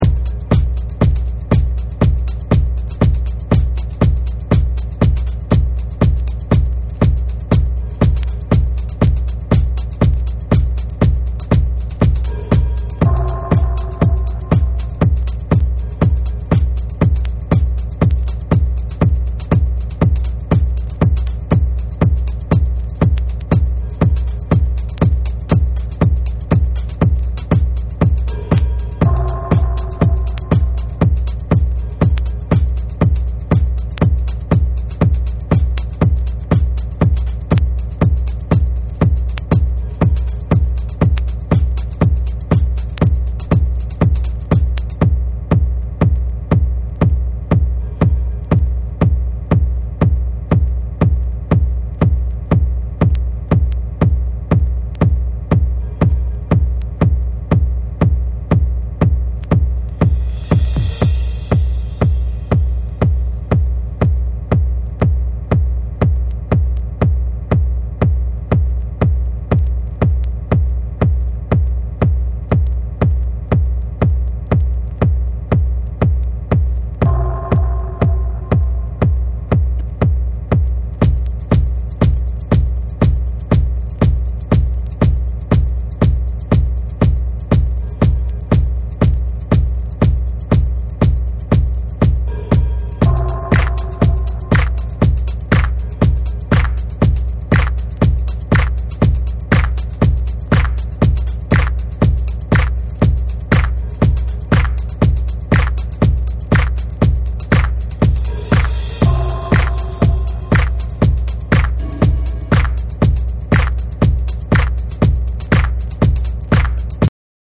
その他ビート、上音のパーツを収録。
JAPANESE / BREAKBEATS